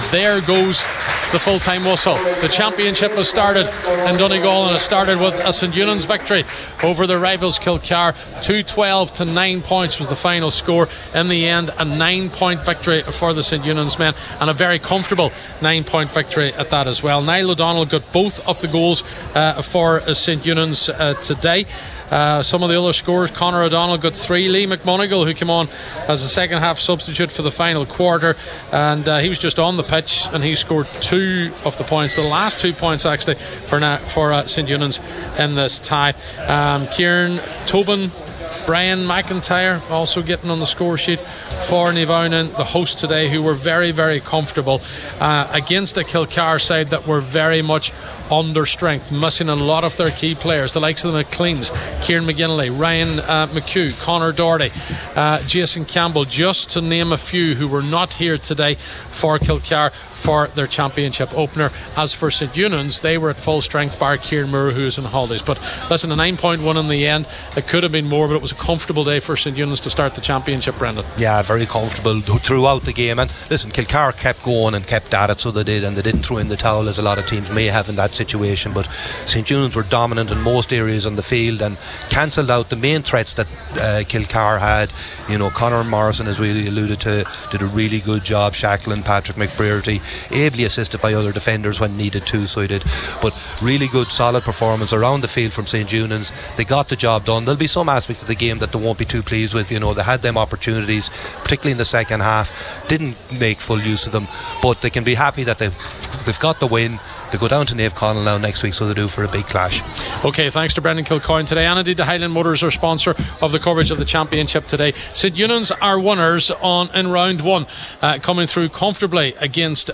match reaction